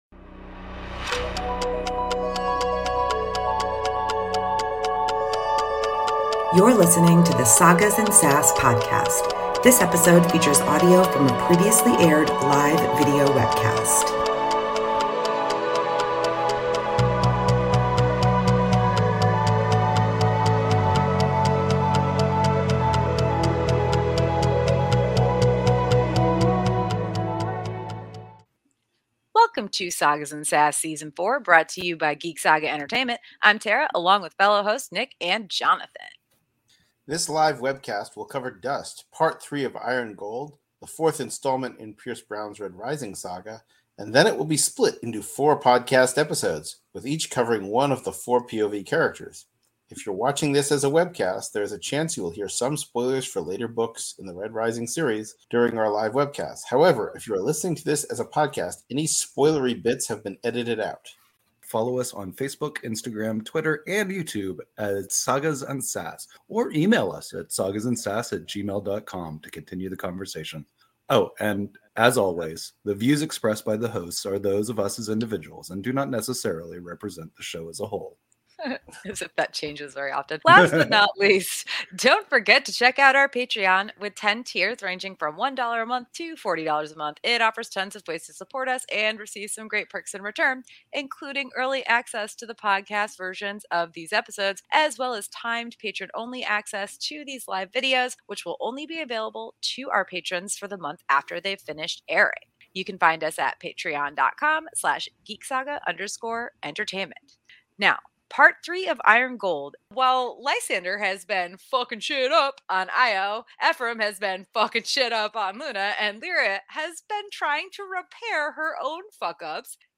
Episode 65 of the Sagas & Sass Podcast originally aired as a live webcast on April 5, 2023.